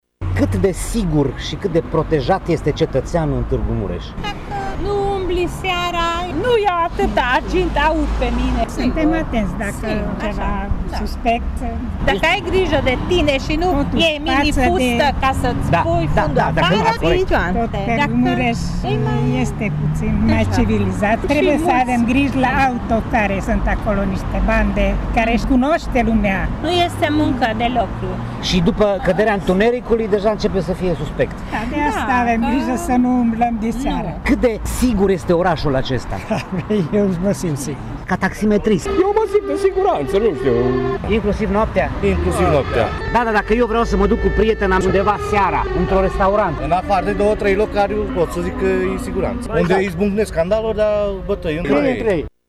Târgumureșenii intervivați spun că se simt în siguranță în orașul lor, dar evită ieșirile noaptea, mai ales în anumite zone: